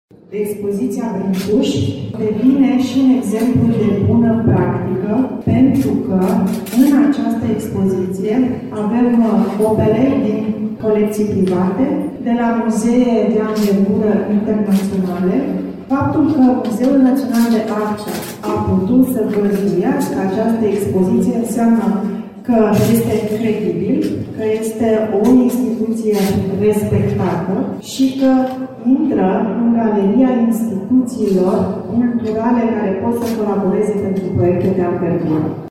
Deschiderea oficială a avut loc în prezența premierului Marcel Ciolacu și a ministrului Culturii, Raluca Turcan.
Expoziția Brâncuși de la Timișoara este un exemplu de bune practici, a declarat ministrul Raluca Turcan.